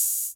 Closed Hats
kanye hats_28-06.wav